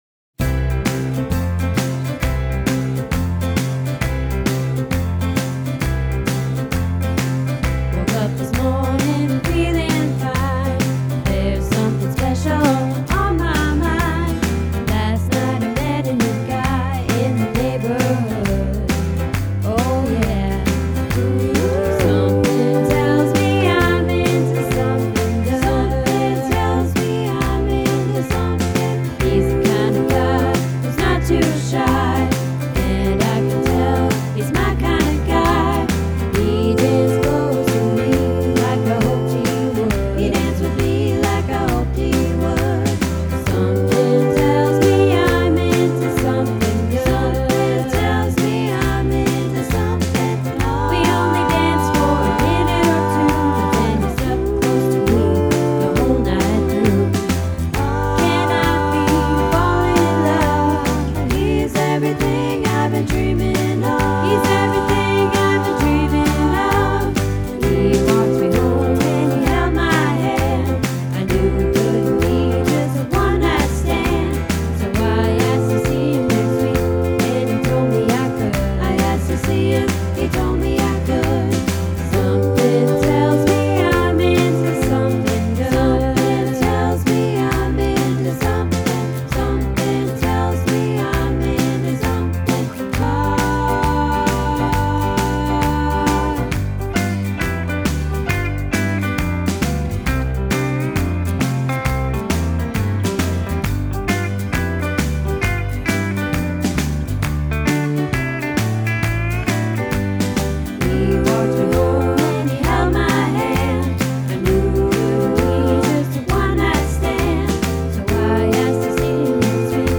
Into Something Good - Practice